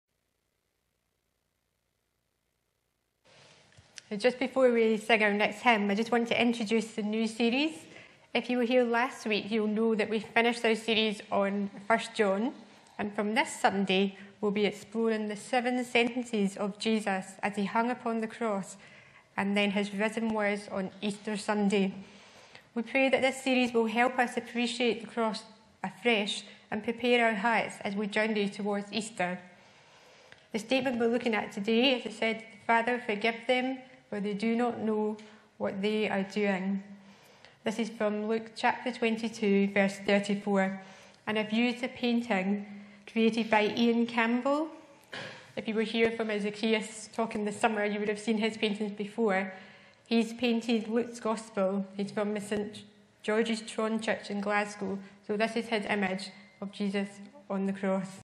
Jesus said …. sermon series intro (BMC)
Bible references: N/A Location: Upper Braes Parish Church – Brightons Ministry Centre Show sermon text Sermon keypoints: Jesus said .... words from the cross Our salvation (BMC) Remember (BMC) Jesus said …. sermon series intro (BMC) Forgiveness (BMC)